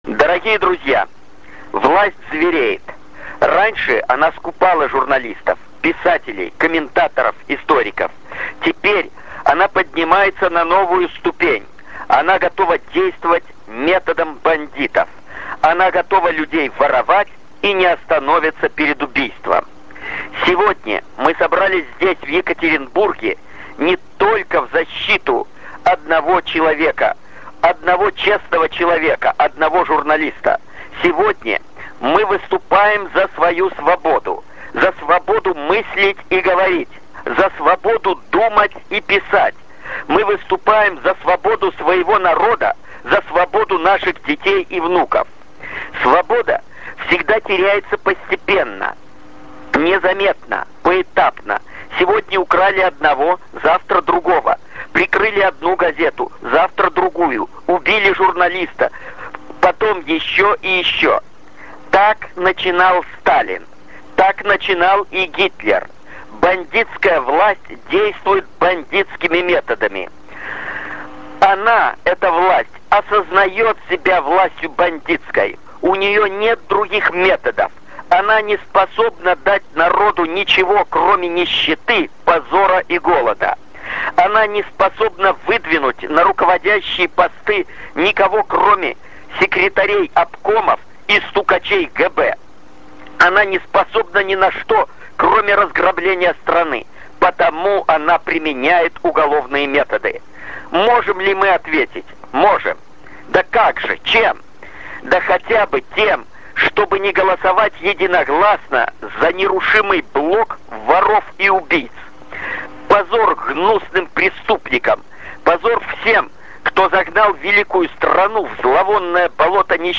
На митинге были заслушаны выступления бывшего политзаключенного, известного правозащитника, писателя и публициста Владимира БУКОВСКОГО, а также военного историка и известного писателя Виктора СУВОРОВА, записанные накануне по телефону.
Выступление Владимира БУКОВСКОГО: